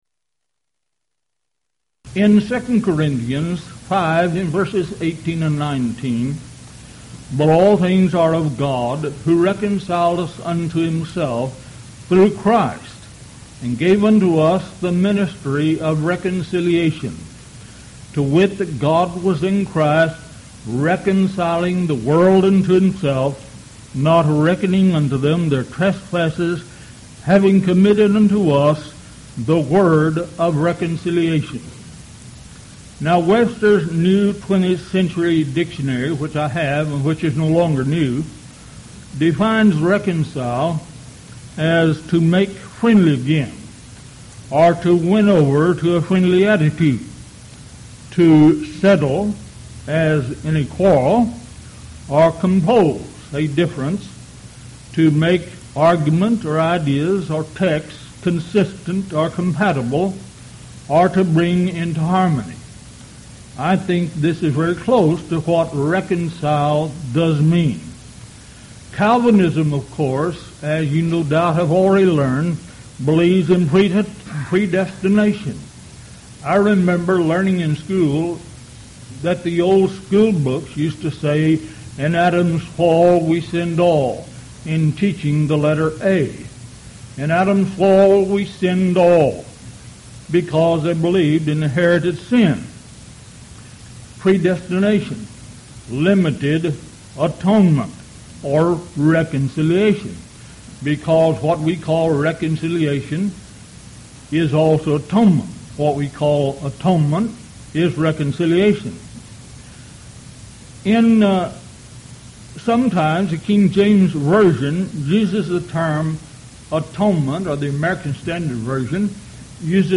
Event: 1998 Houston College of the Bible Lectures
lecture